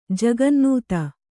♪ jagannūta